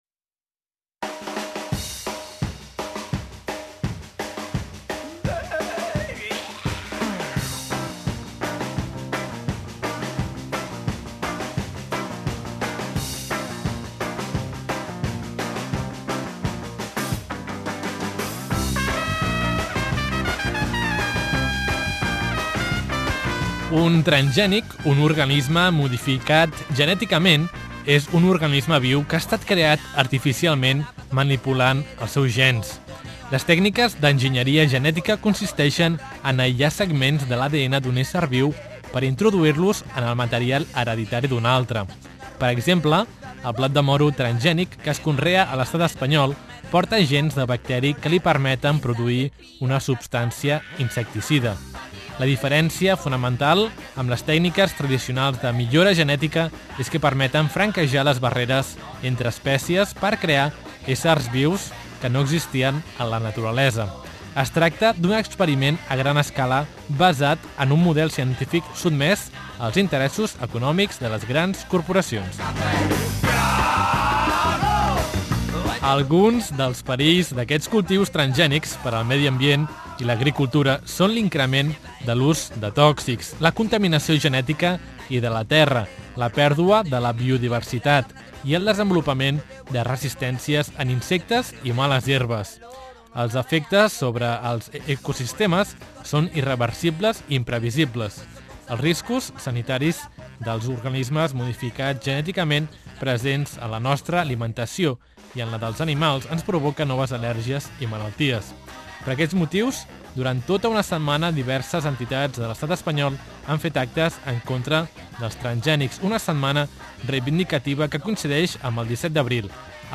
Escolta el REPORTATGE sobre els transgènics